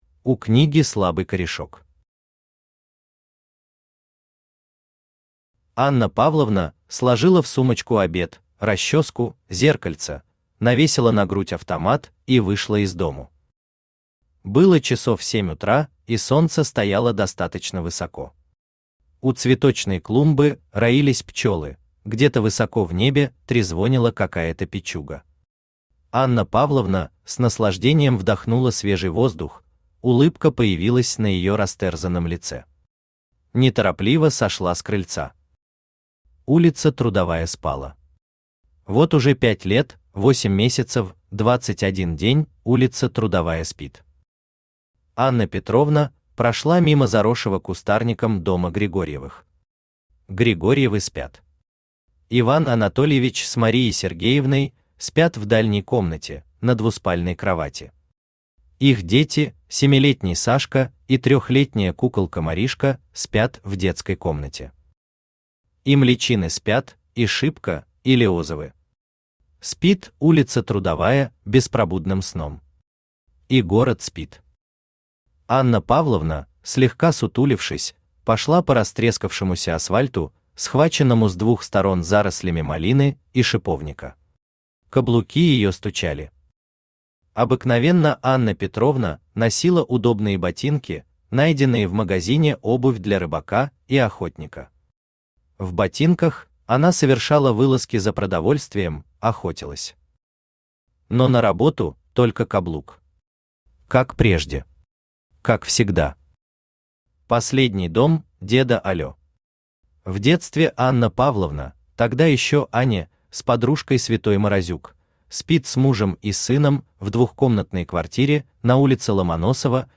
Аудиокнига У книги слабый корешок | Библиотека аудиокниг
Aудиокнига У книги слабый корешок Автор Василий Дмитриевич Гавриленко Читает аудиокнигу Авточтец ЛитРес.